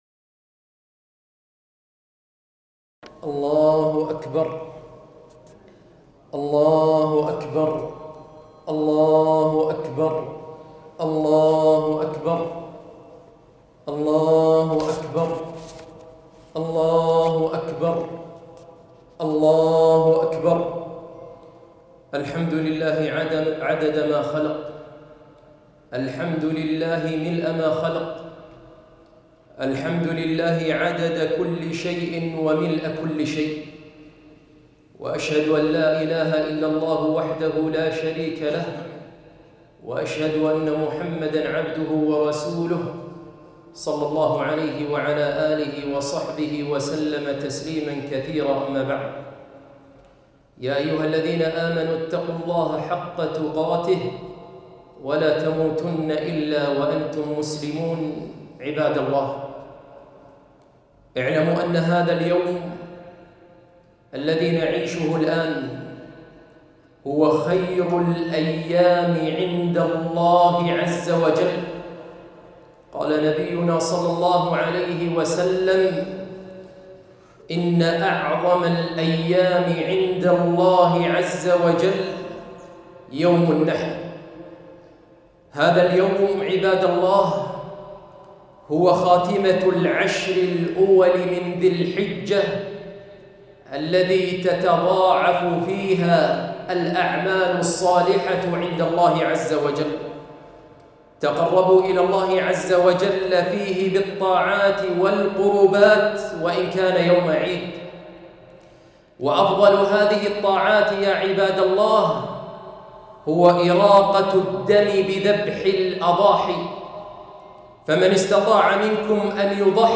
خطبة عيد الأضحي لعام 1442